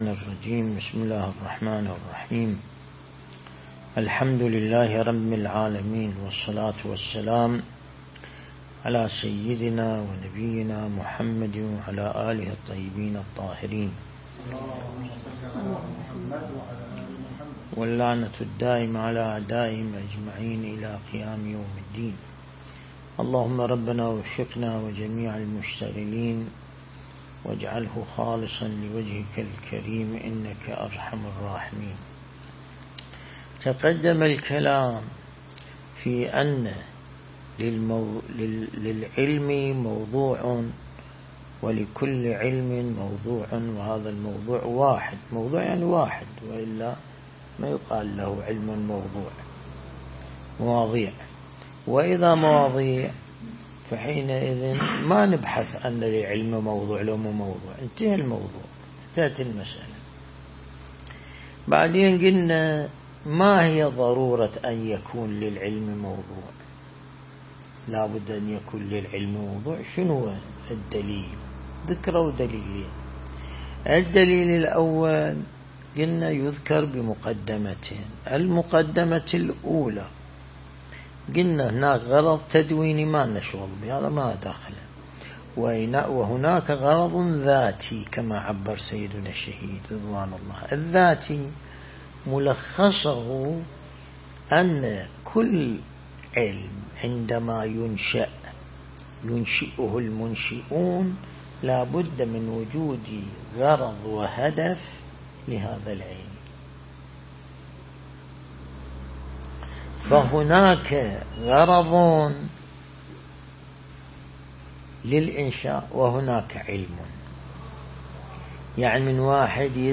درس البحث الخارج الأصول (31)
النجف الأشرف